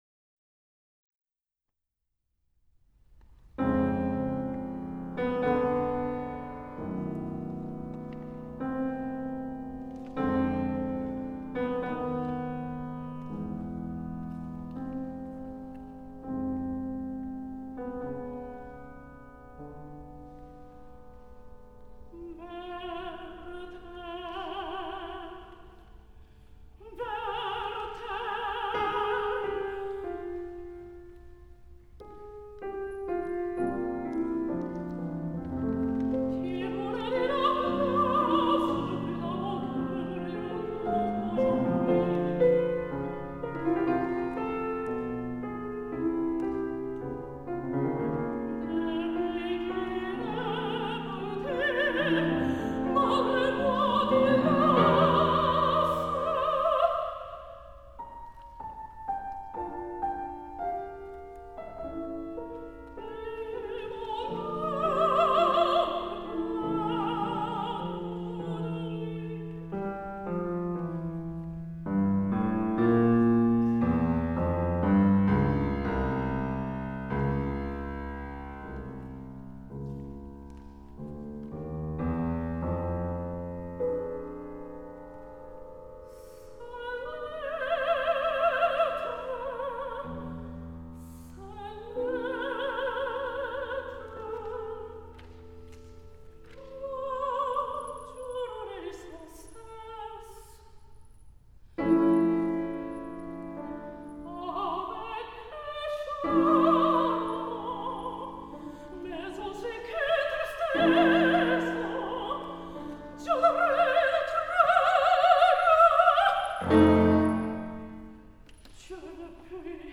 過去の演奏のご紹介
Mezzo-Soprano
Piano